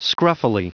Prononciation du mot scruffily en anglais (fichier audio)
Prononciation du mot : scruffily